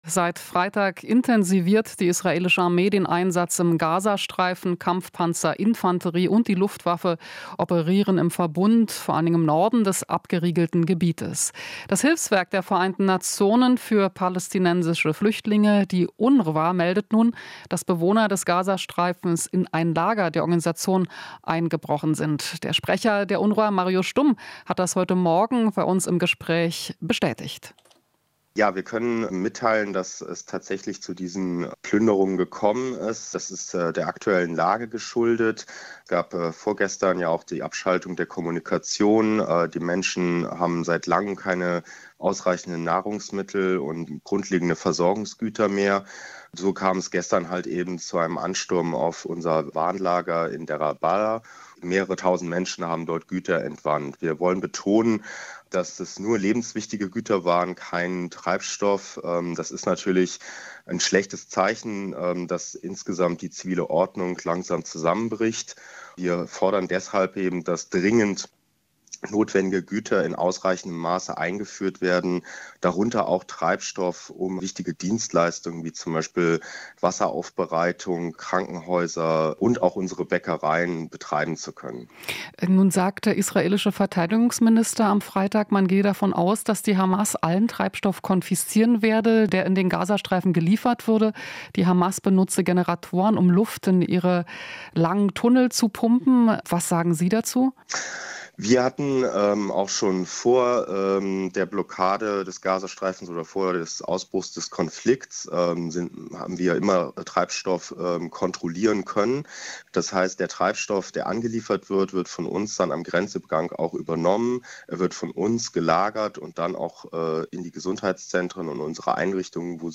Interview - UNRWA: Zivile Ordnung in Gaza bricht langsam zusammen